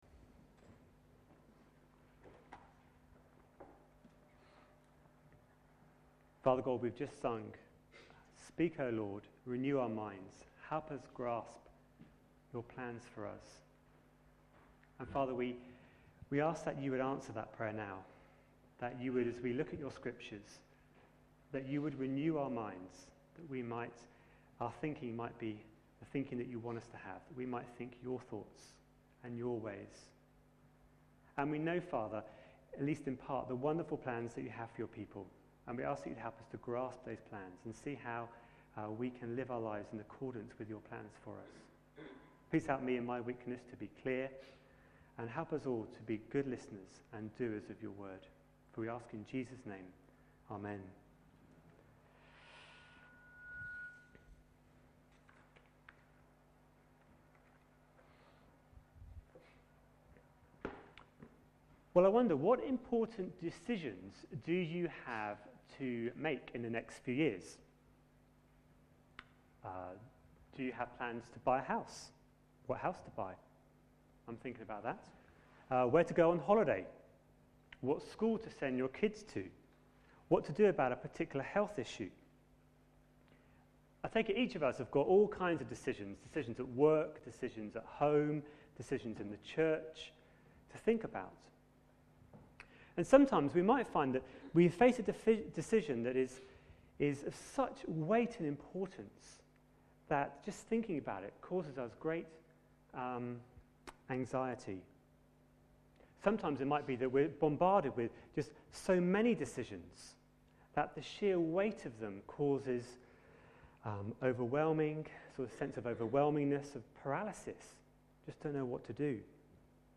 A sermon preached on 23rd January, 2011, as part of our What does the Bible say about... series.